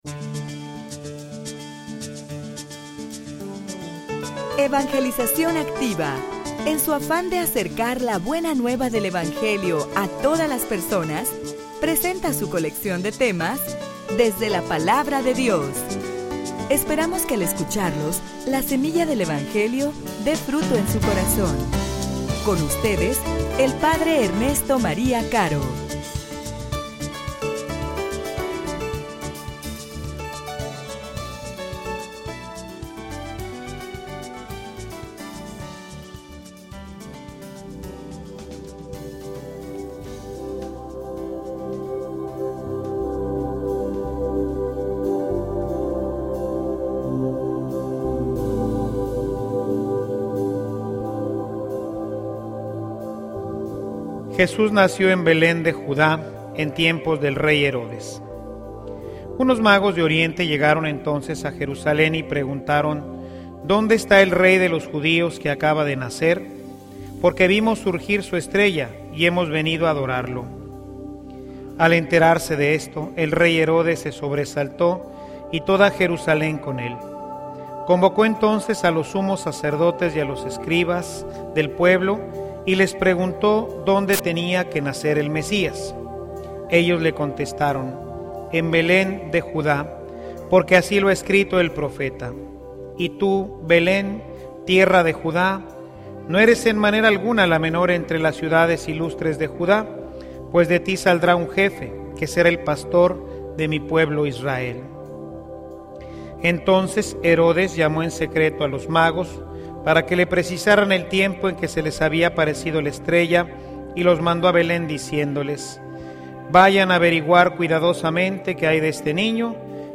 homilia_Manifiesta_a_Cristo_en_tu_vida.mp3